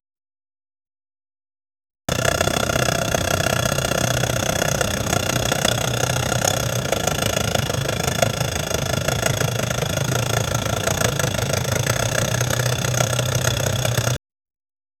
Sound of motorcycle
sound-of-motorcycle-ue6ld4xo.wav